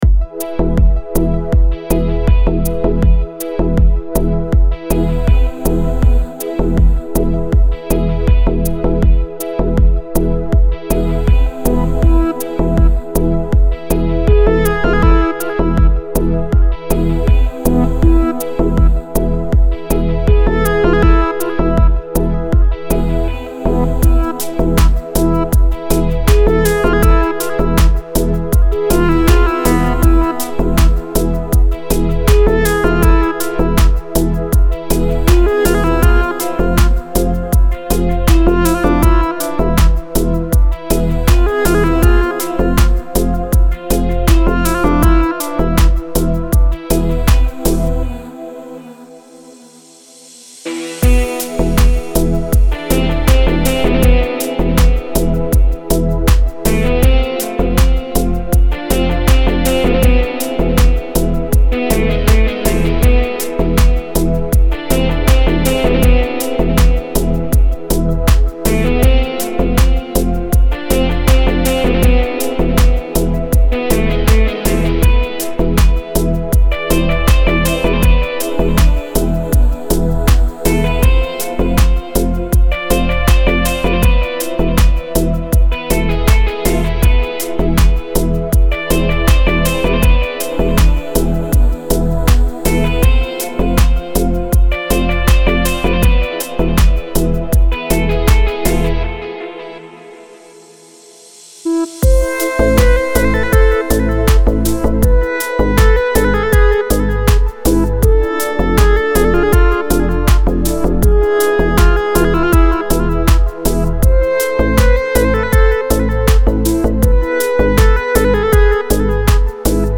دیپ هاوس
ریتمیک آرام